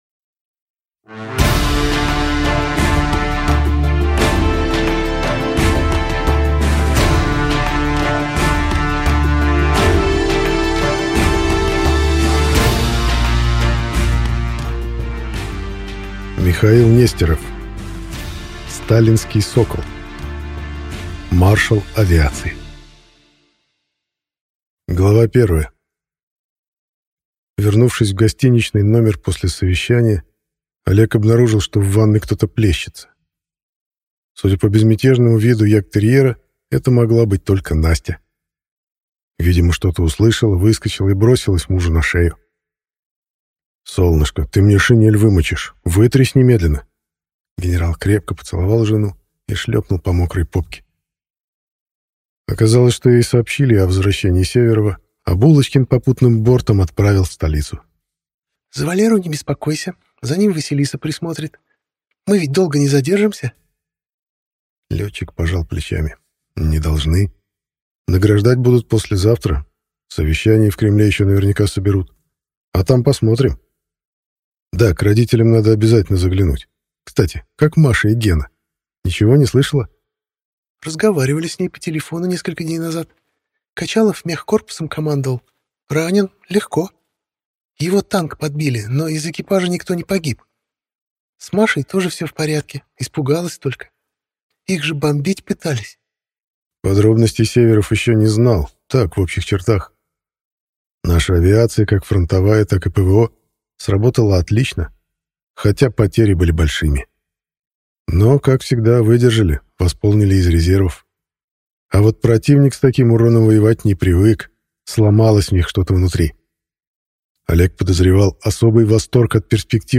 Аудиокнига Сталинский сокол. Маршал авиации | Библиотека аудиокниг